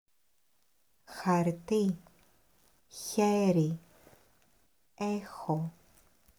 2. Le son χ : pas un "k", pas un "ch"
Le χ est un "h" rauque, qu'on appelle une fricative vélaire sourde.
• χαρτί (papier) → [khartí], pas chartí ou kartí
💡 Attention : Le son est soufflé à l’arrière de la bouche, sans être trop dur.